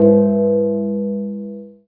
churchbell.wav